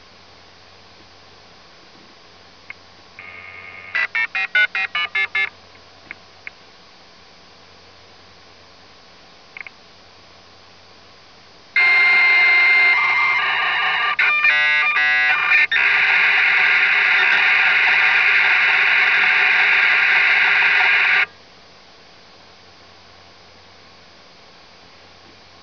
This dial sound was recoreded from my SupraExpress 33.6kbps modem.
modem.aiff